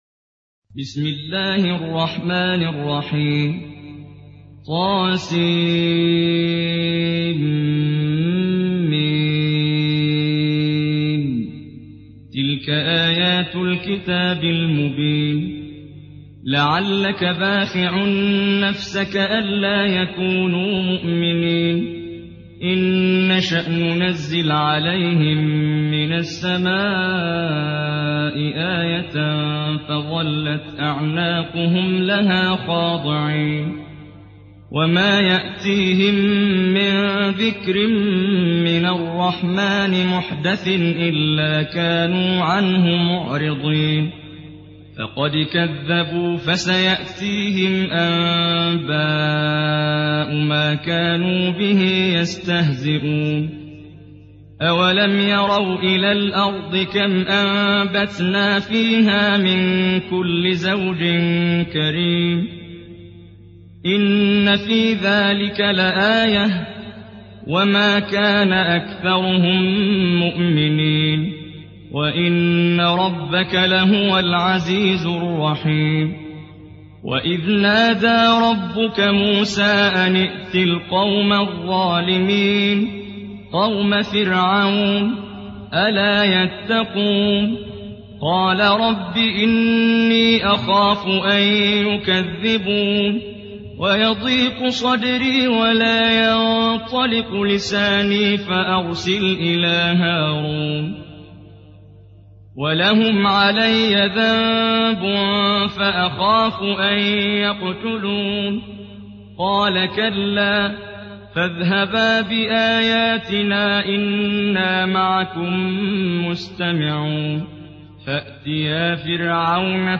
Surah Şuara MP3 by Muhammad Jibreel in Hafs An Asim narration.
Murattal Hafs An Asim